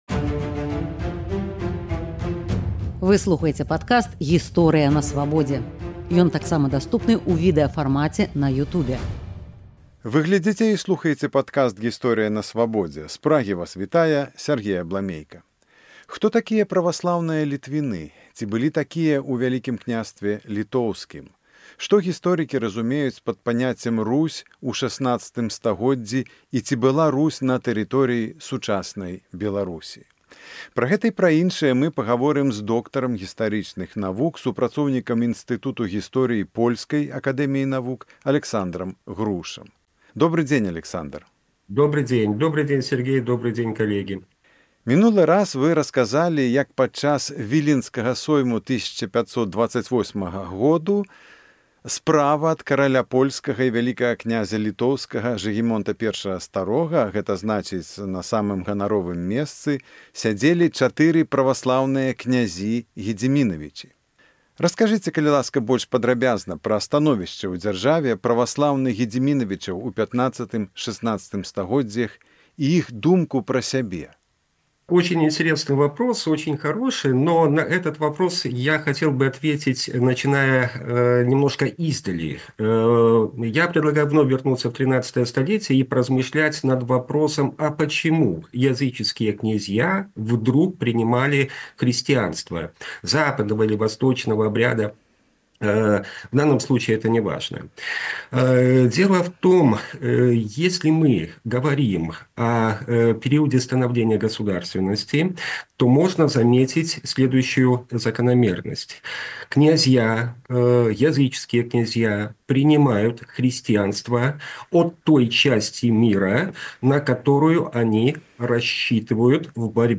Што гісторыкі разумеюць пад паняцьцем «Русь» у XVI стагодзьдзі і ці была Русь на тэрыторыі сучаснай Беларусі? Гаворым з доктарам гістарычных навук